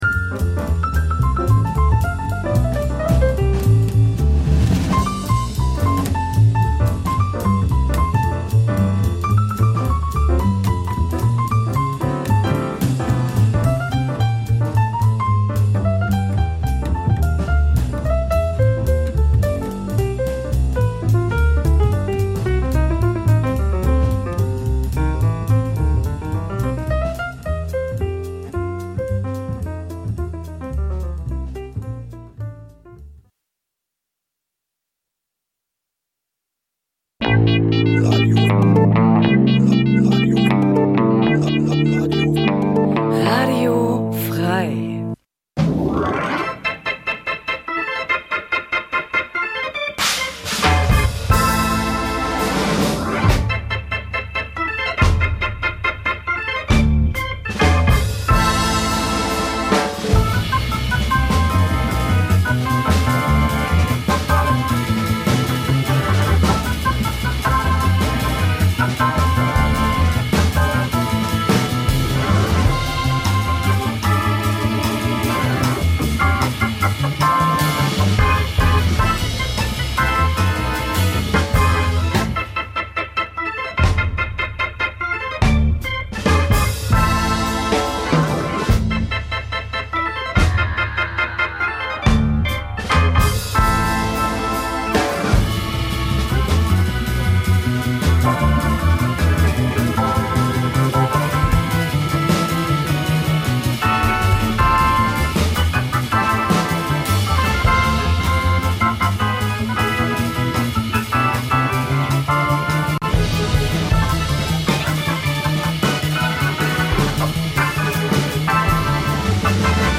Zwei Musikfreunde duellieren sich mit raren Rock- und Punklegenden... - vinyl meets mp3... crossover zwischen den Welten, Urlaubsmusik und Undergroundperlen.
Einmal im Monat Freitag 21-22 Uhr Live on Air und in der Wiederholung montags drauf 12 Uhr.